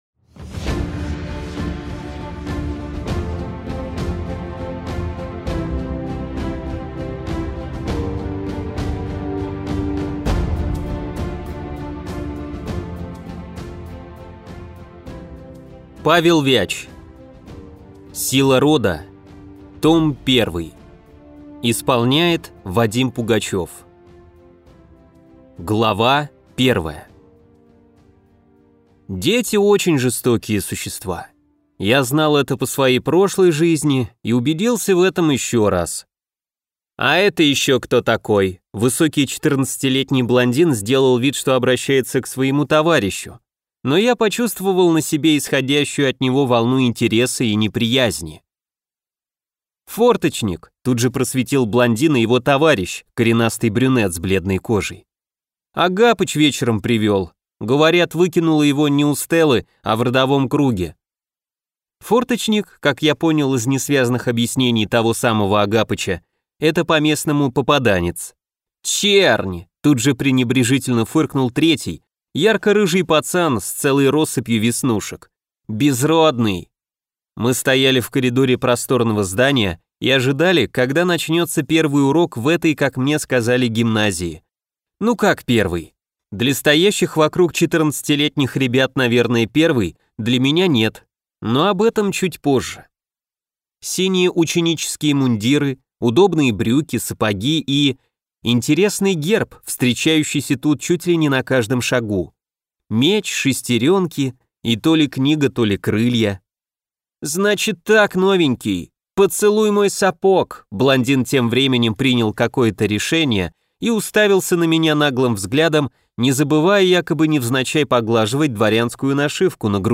Аудиокнига Сила рода | Библиотека аудиокниг
Прослушать и бесплатно скачать фрагмент аудиокниги